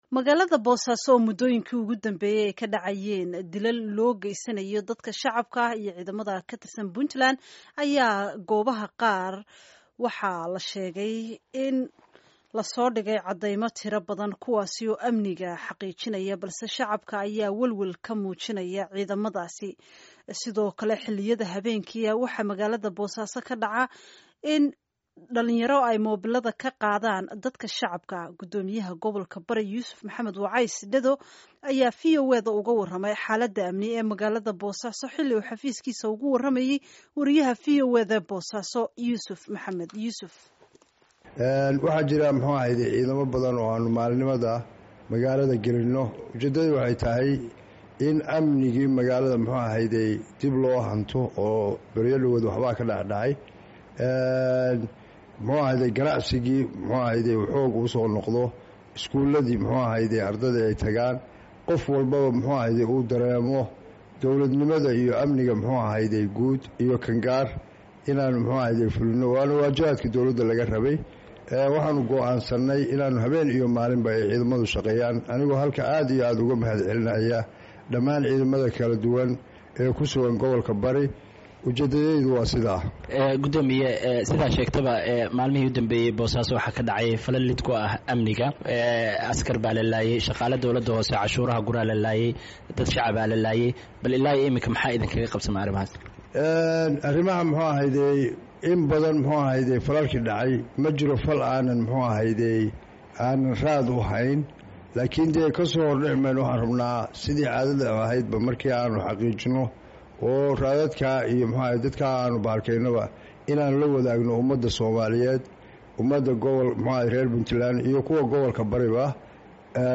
Wareysi: Xaaladda Amni ee Boosaaso